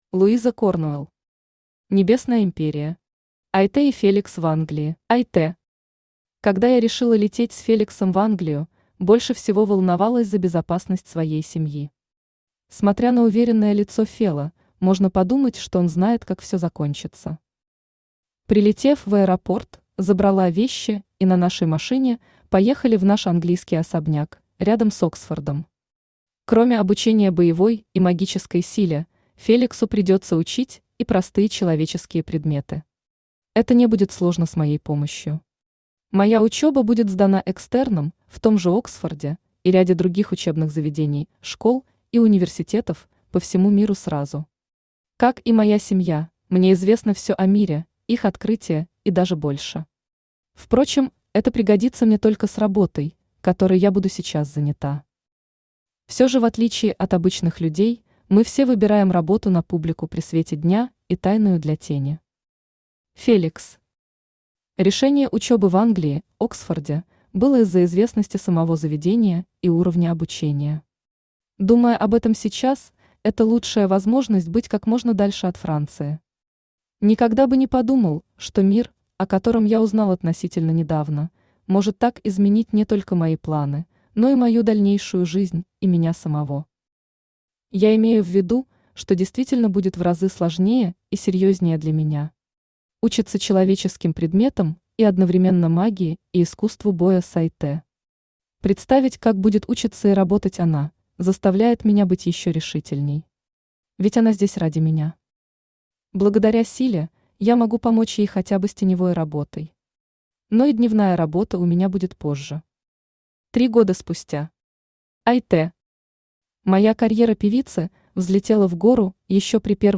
Аудиокнига Небесная Империя. Айтэ и Феликс в Англии | Библиотека аудиокниг
Айтэ и Феликс в Англии Автор Луиза Корнуэл Читает аудиокнигу Авточтец ЛитРес.